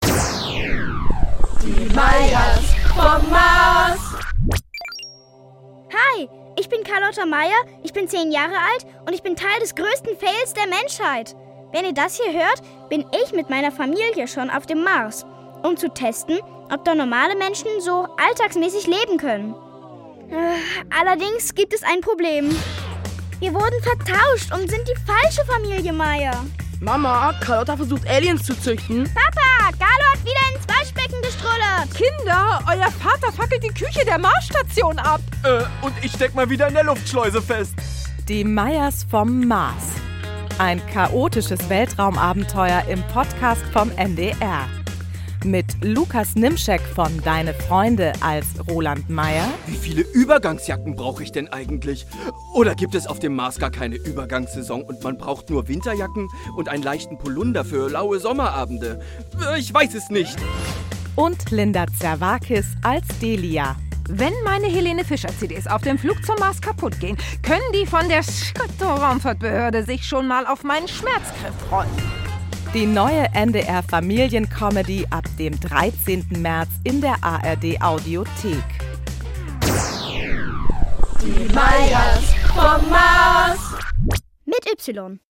Futuristische Familien-Comedy von Martin Tietjen für alle ab 8
Jahren als Hörspiel-Podcast u.a. mit Linda Zervakis